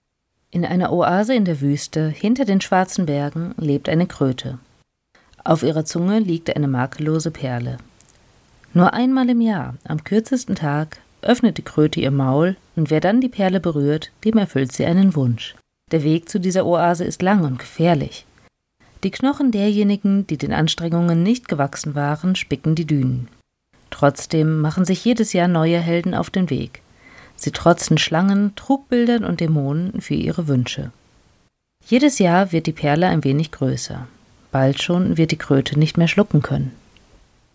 Natürlich gibt es noch eine Menge zu lernen – was das Vorlesen angeht genau so wie in Bezug auf die Technik-Technik.
PS: Falls ihr im Hintergrund ein gleichmäßiges Summen wahrnehmt – nein, das ist kein ultracooler Rausch-Effekt, sondern der schnurrende Kater auf dem Sofa hinter mir.